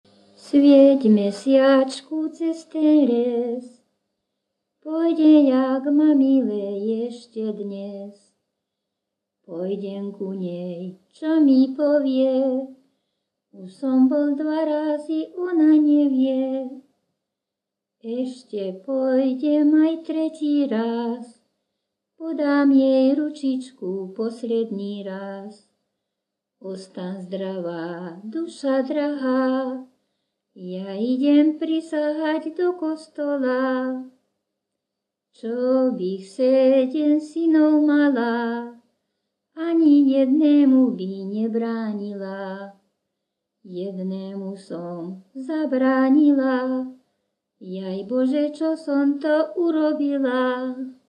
Descripton sólo ženský spev bez hudobného sprievodu
Place of capture Litava
Key words ľudová pieseň